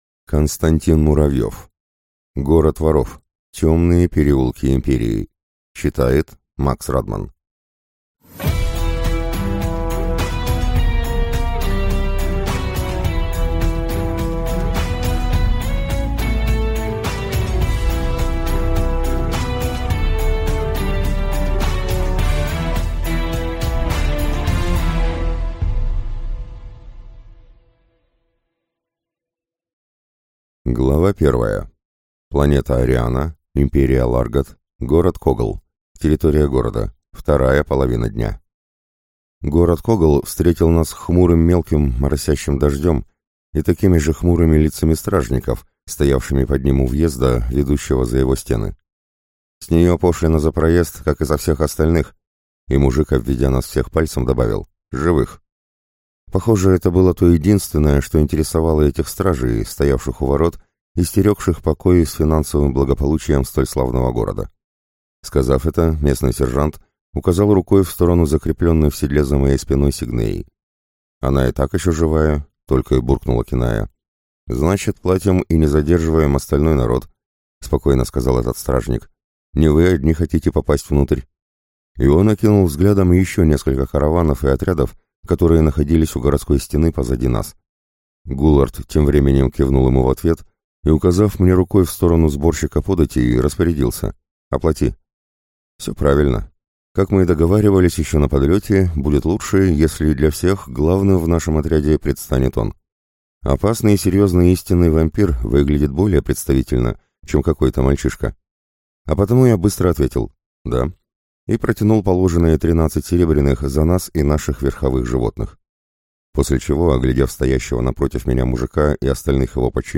Аудиокнига Город воров. Темные переулки Империи | Библиотека аудиокниг